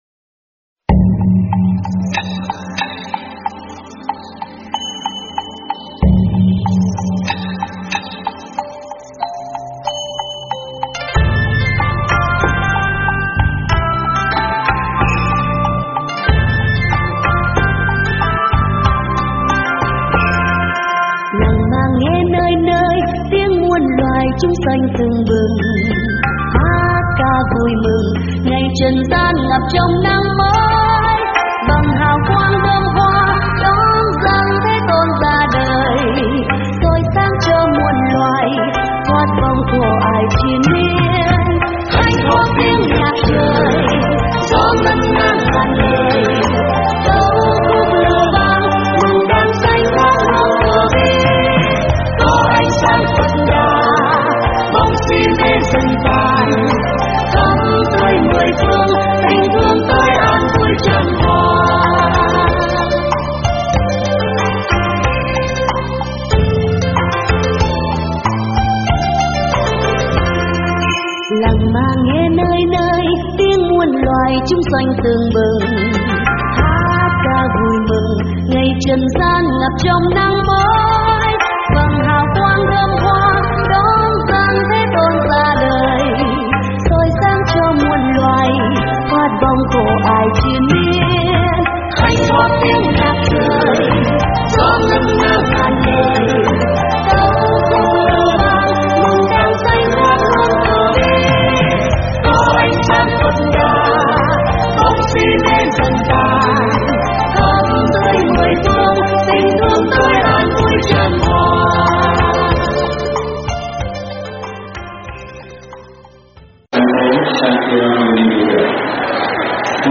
Mời quý phật tử nghe mp3 Lễ Phật Đản Sanh PL 2642 tại tu viện Trúc Lâm năm 2018 - ĐĐ.